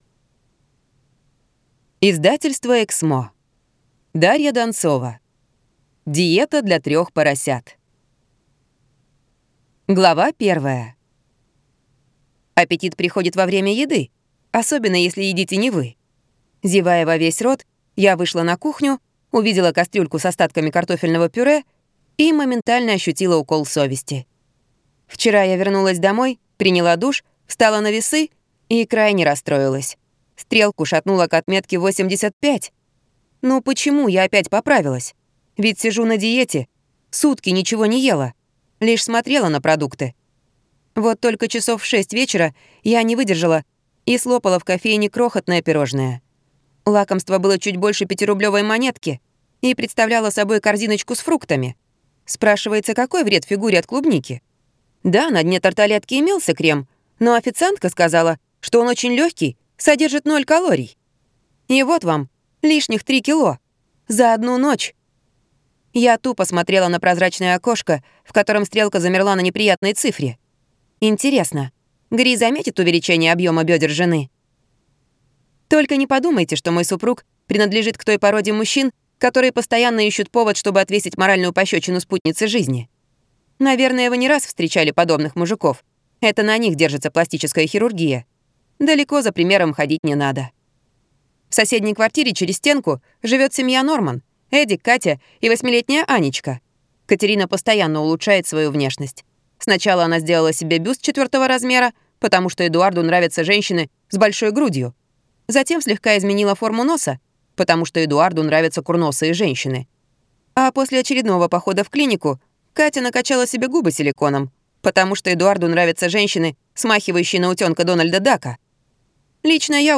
Аудиокнига Диета для трех поросят | Библиотека аудиокниг